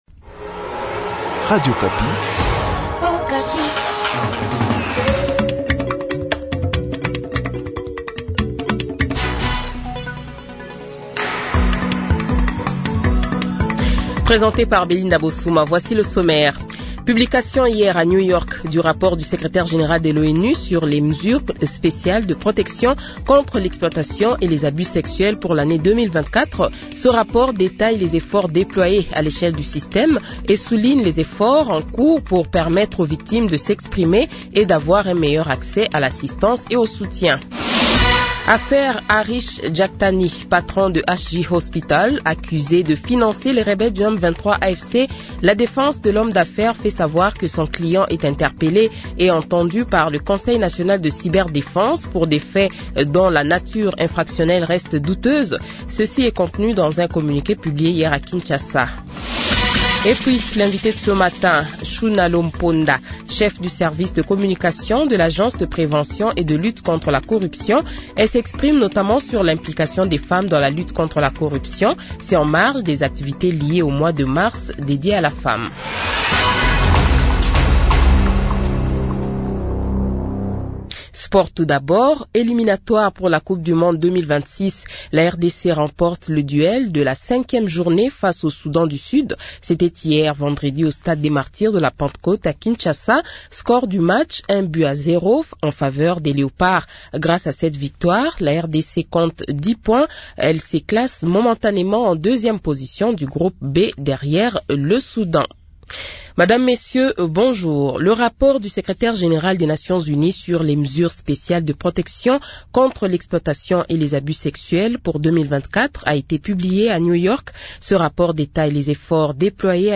Le Journal de 7h, 22 Mars 2025 :